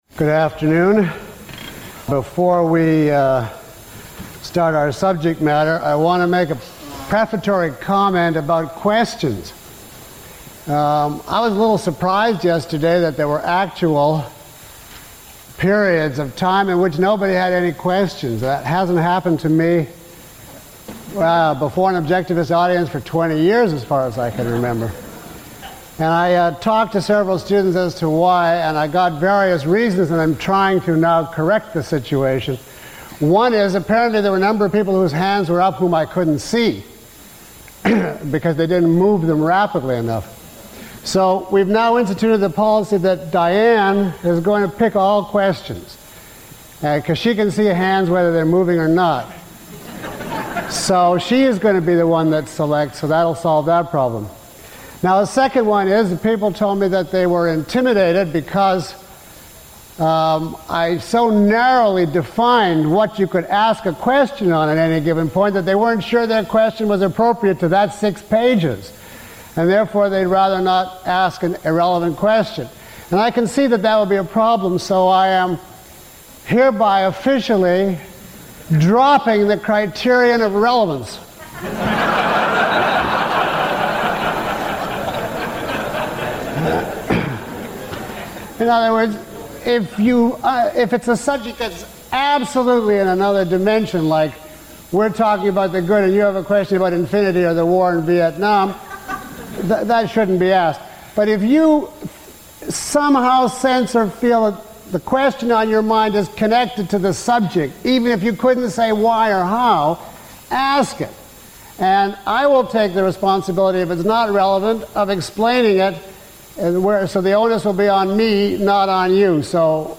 Below is a list of questions from the audience taken from this lecture, along with (approximate) time stamps.
Lecture 11 - Advanced Seminars on Objectivism The Philosophy of Ayn Rand.mp3